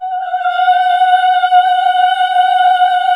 AAH F#3 -L.wav